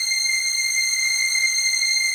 DM PAD3-12.wav